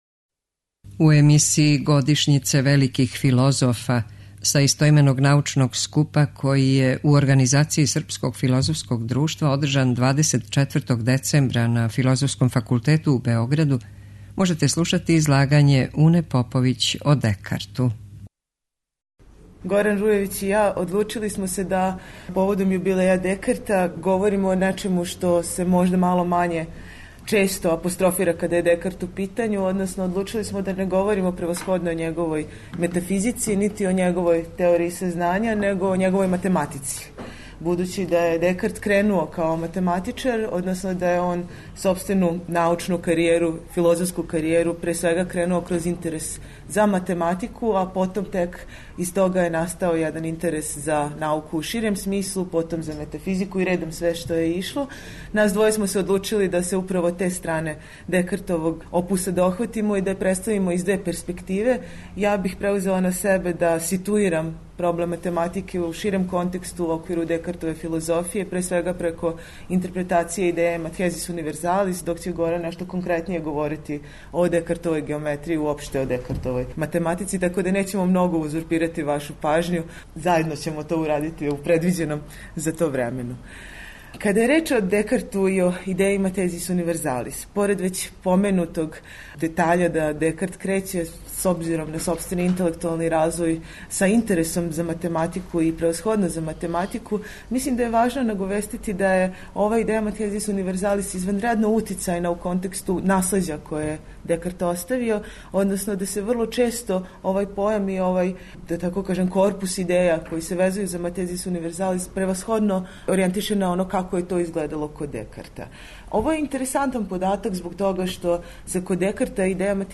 са истоименог научног скупа одржаног 24. децембра 2016. на Филозофском факултету у Београду